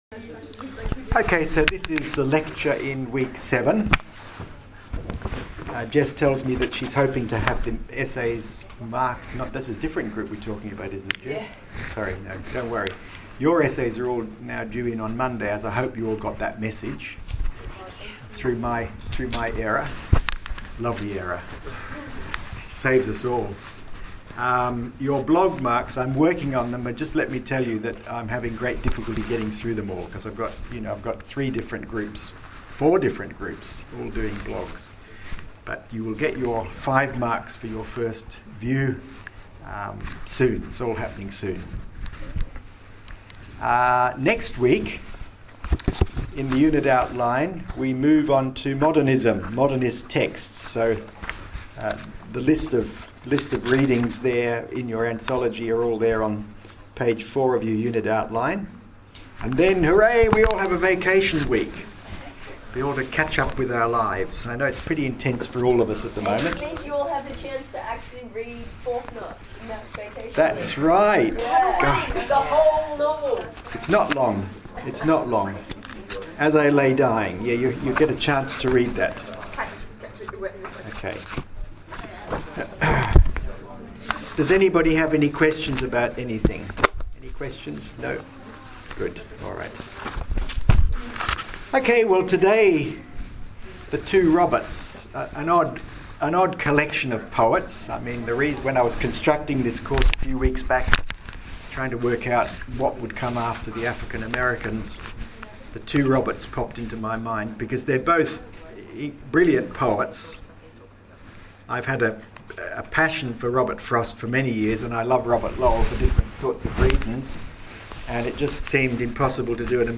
If you want to hear the whole of my talk on these two poets then please click here: